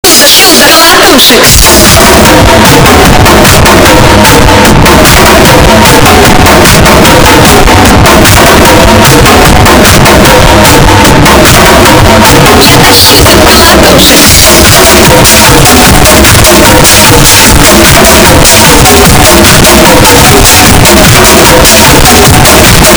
More Sounds in Earrape Soundboard